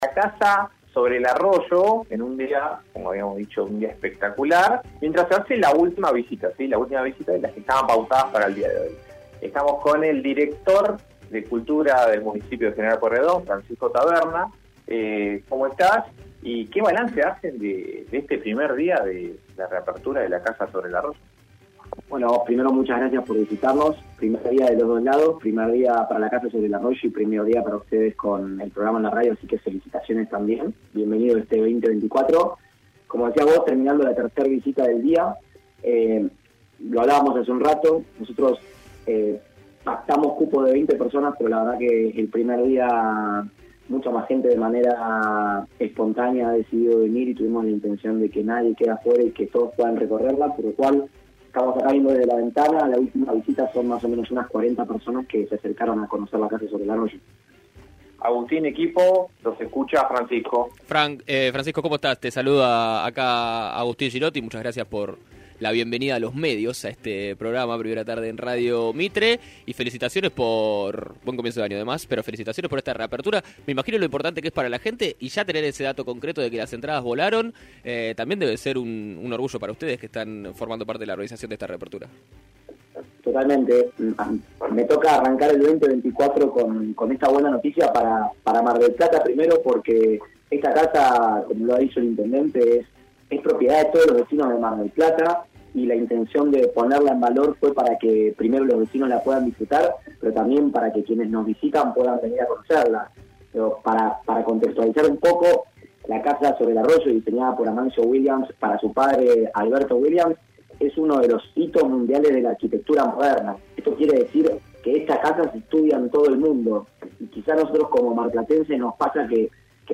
Porque la casa es propiedad de todos los vecinos y la intención de ponerla en valor fue para que además los turistas puedan conocerla", indicó el funcionario municipal a "Primera Tarde" de Mitre (FM 103.7).